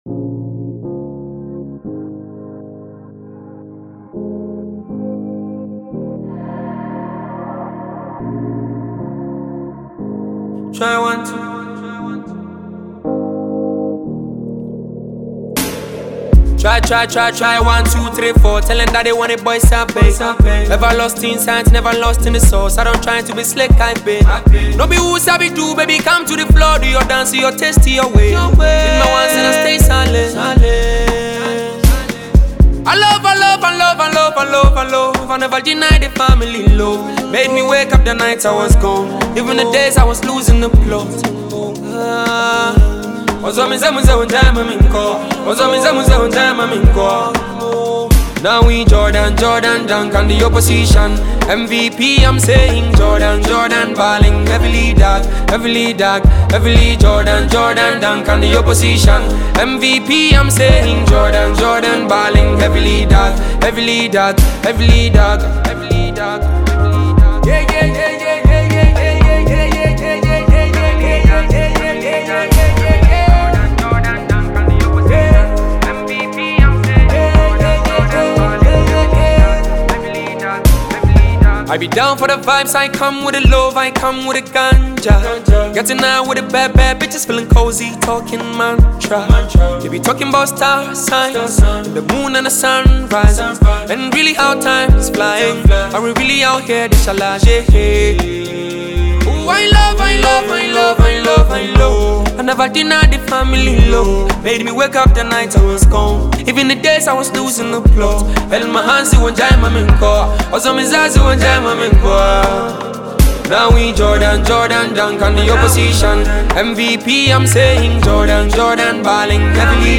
blending highlife, Afrobeat, and trap influences.